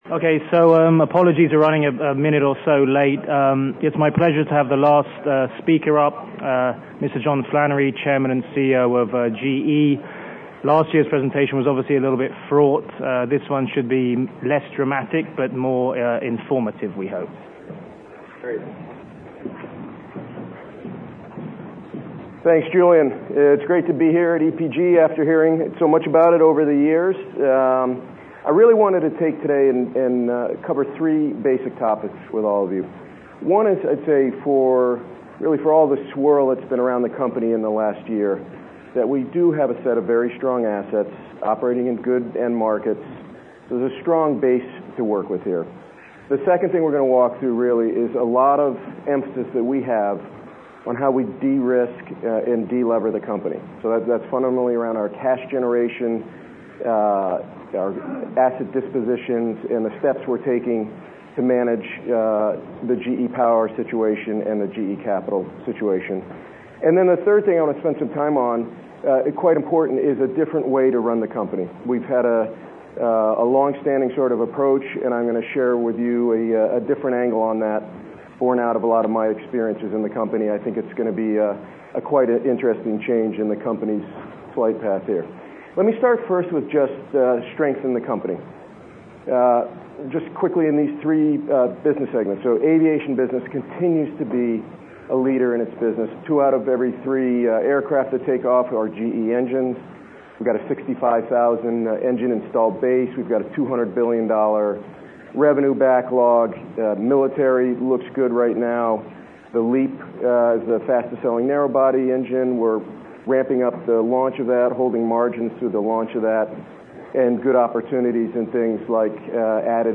John Flannery, GE Chairman and CEO, presented.